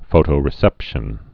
(fōtō-rĭ-sĕpshən)